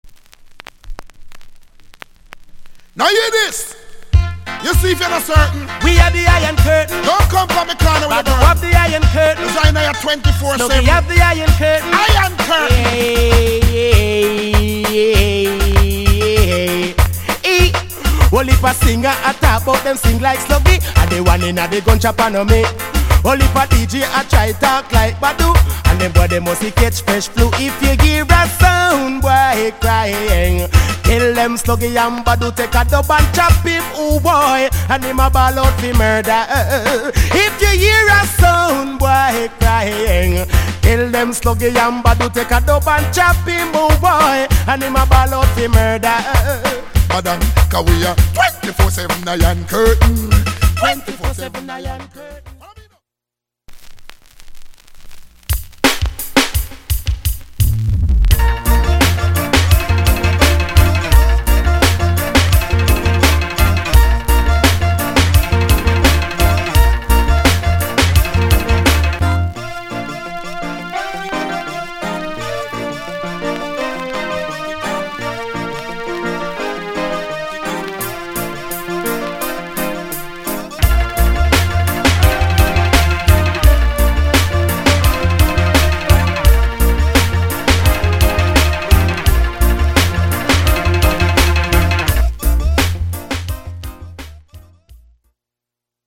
Good Combi. Sound Tune